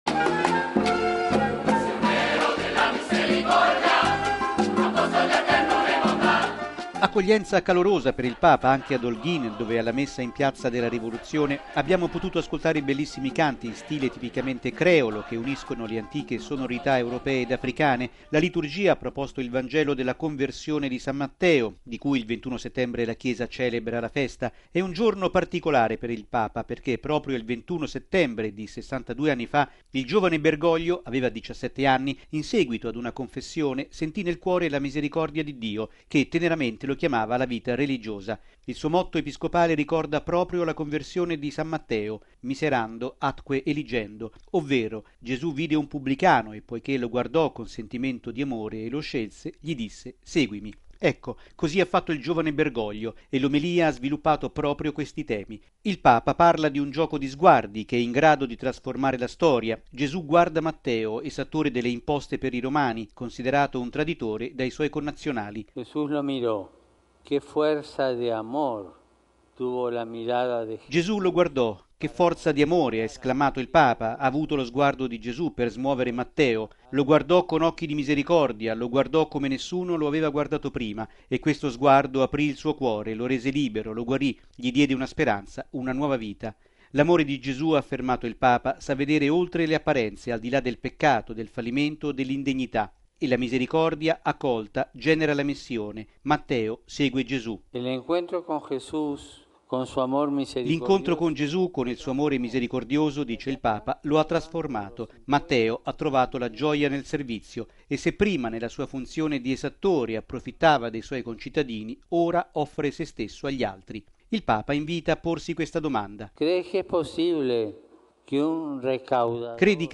Accoglienza calorosa per il Papa anche ad Holguín, dove alla Messa in Piazza della Rivoluzione abbiamo potuto ascoltare i bellissimi canti in stile tipicamente creolo, che uniscono le antiche sonorità europee ed africane.